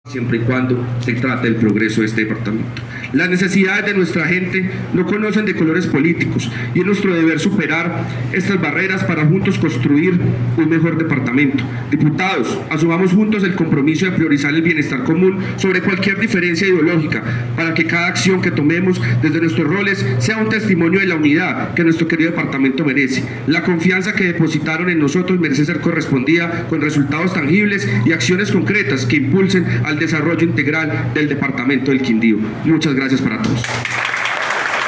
AUDIO: HUGO ANDRÉS ARISTIZÁBAL MARÍN, PRESIDENTE DE LA ASAMBLEA  DEPARTAMENTAL DEL QUINDÍO